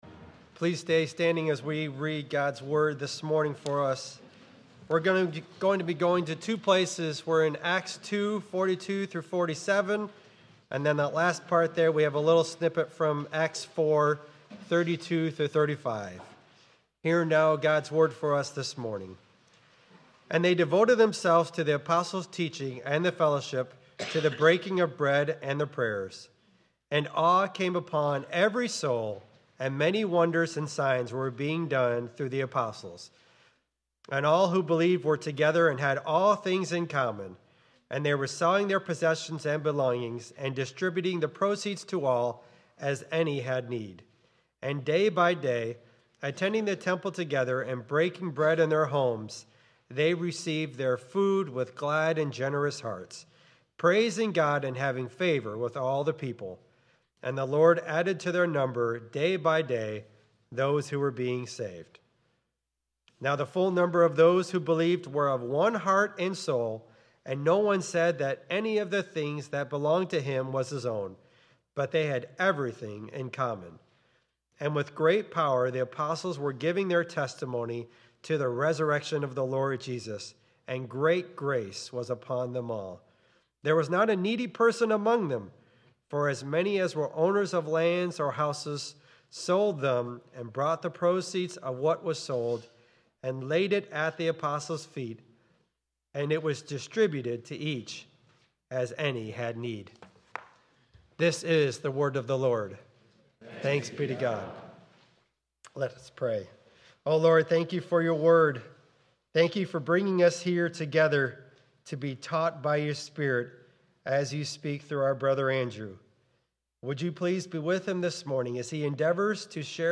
2.22.26 sermon.m4a